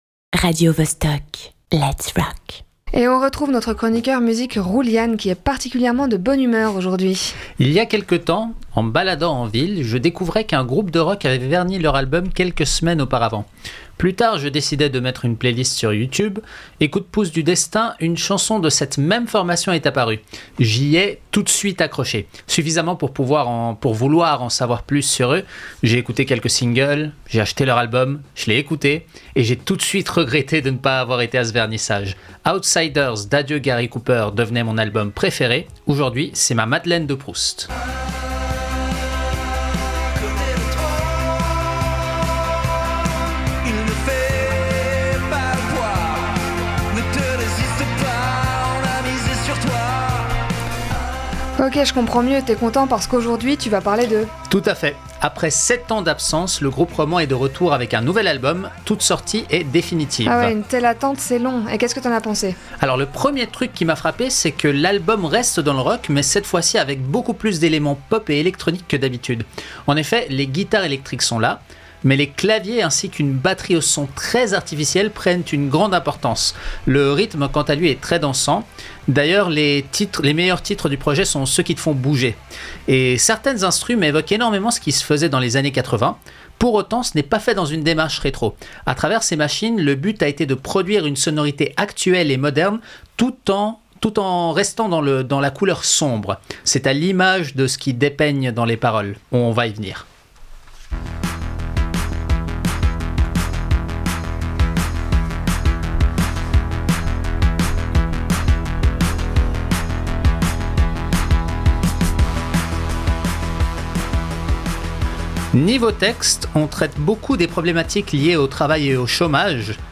Animation
Chronique